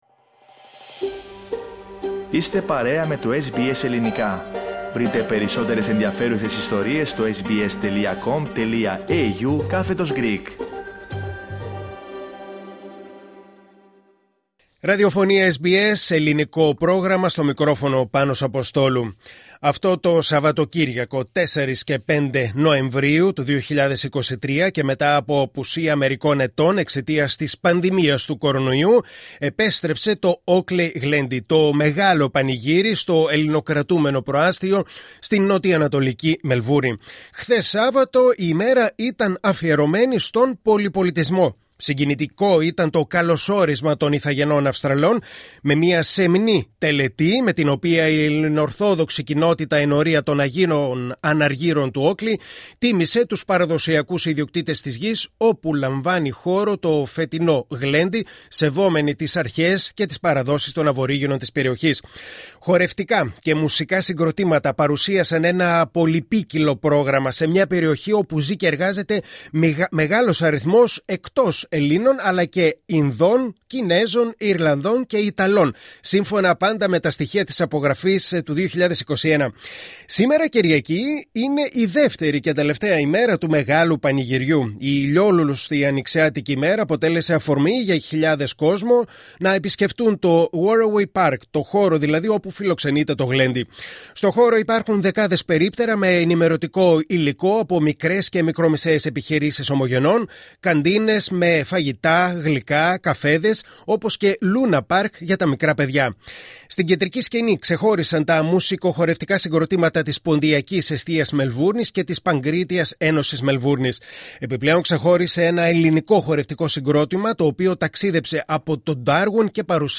Ολοκληρώθηκε σήμερα το Oakleigh Glendi, το μεγάλο ελληνικό και πολυπολιτισμικό πανηγύρι στο Οκλι της Μελβούρνης – Χιλιάδες οι επισκέπτες το διήμερο – Σήμερα έγινε η επίσημη έναρξη και το SBS Greek βρέθηκε και μίλησε με επίσημους φορείς και παράγοντες της Παροικίας